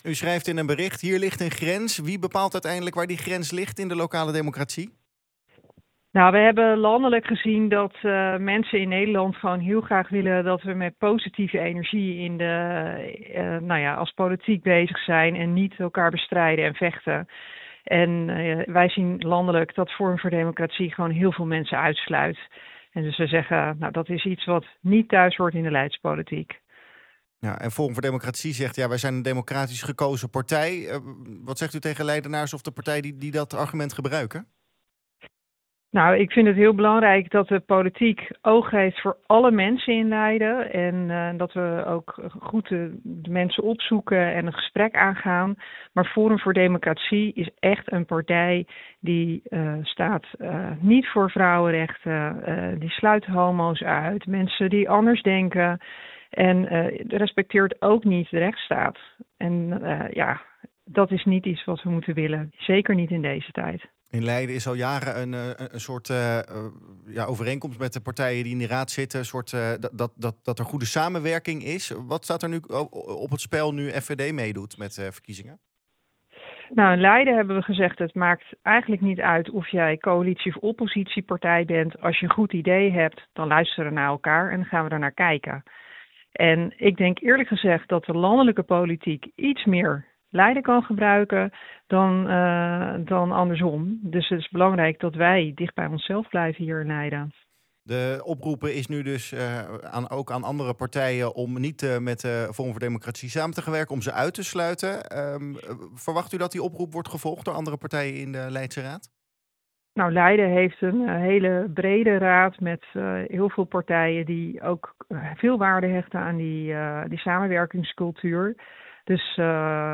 in de ochtendshow over het uitsluiten van FVD.